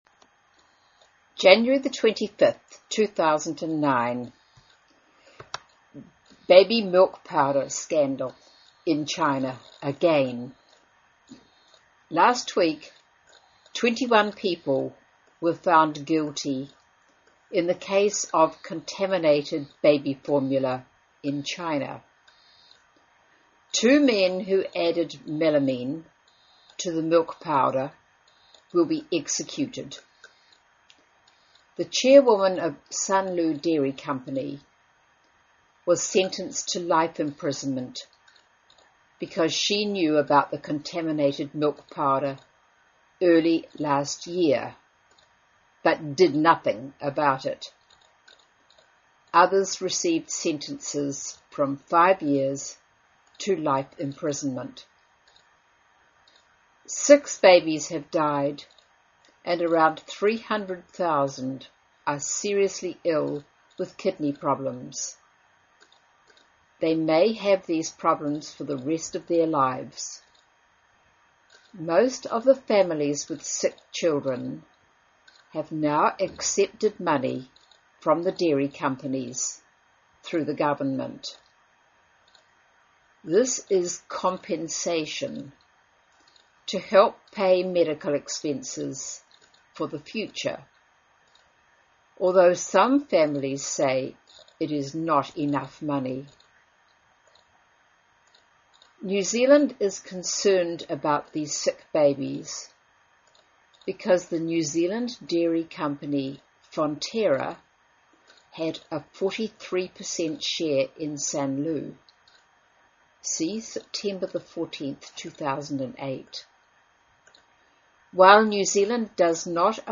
新西兰英语 97 Baby milk powder scandal in China, again 听力文件下载—在线英语听力室